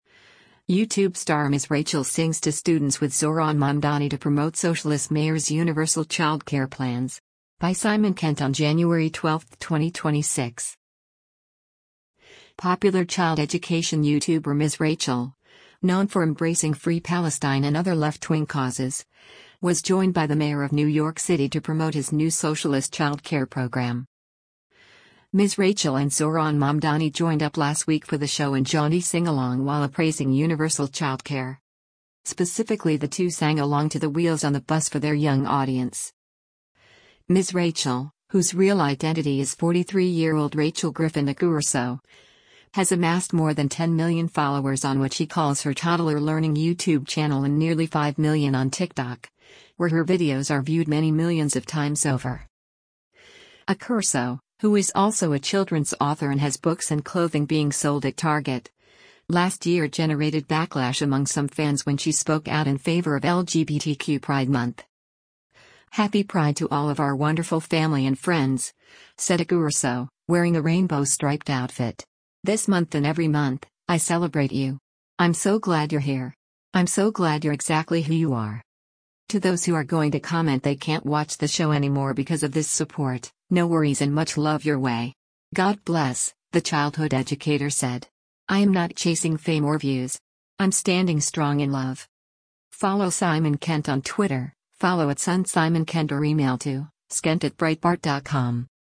Ms. Rachel and Zohran Mamdani joined up last week for the show and jaunty singalong while appraising universal childcare.
Specifically the two sang along to “The Wheels on the Bus” for their young audience.